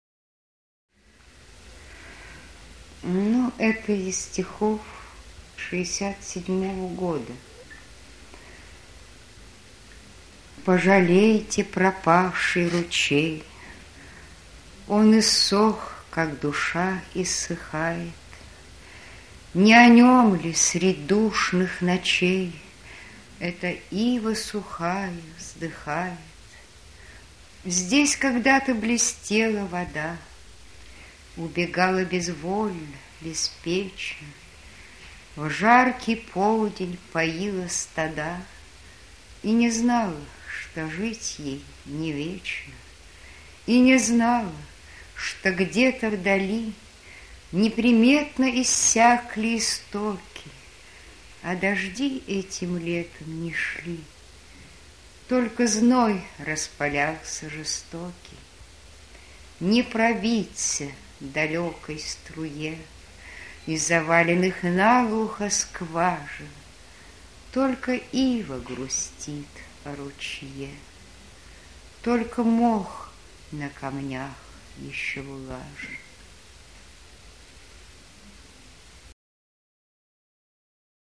А хотите послушать голос самой Марии Петровых?Она замечательно читает свои стихи.........